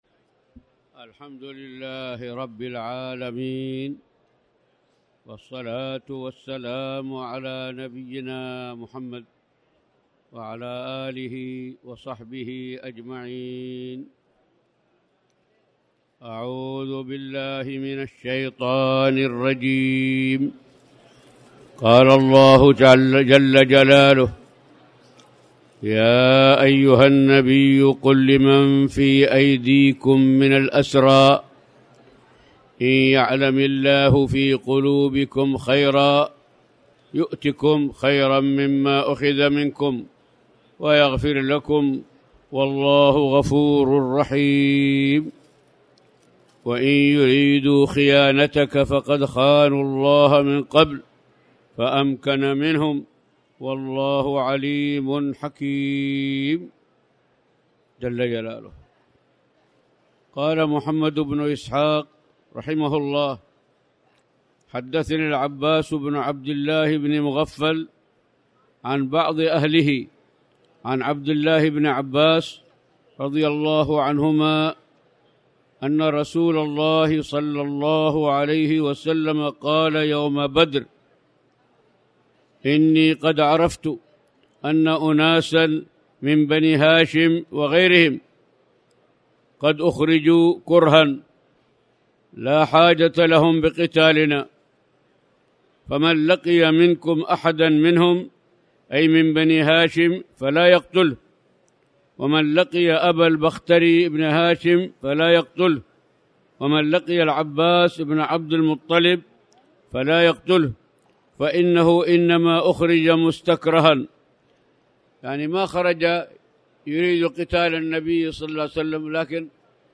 تاريخ النشر ٨ رمضان ١٤٣٩ هـ المكان: المسجد الحرام الشيخ